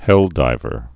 (hĕldīvər)